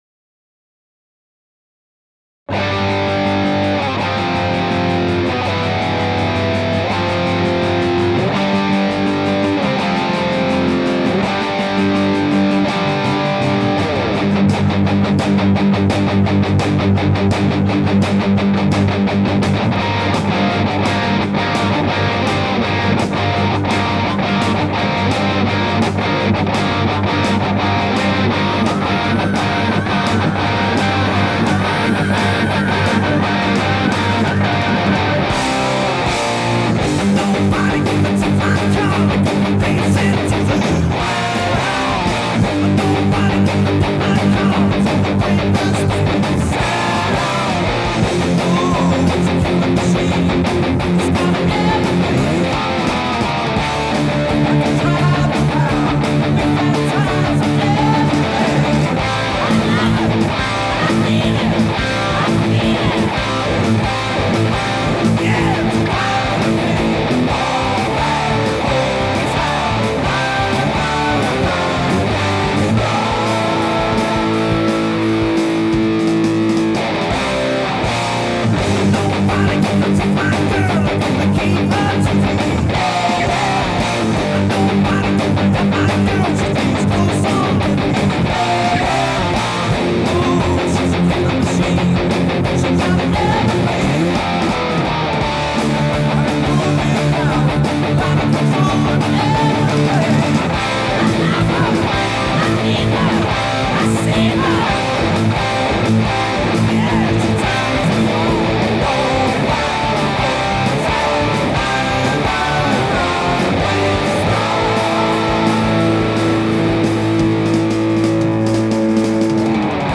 I did this quickie sound clip to see how it sounds recorded.
It's got an Octavia in there doing some cool stuff.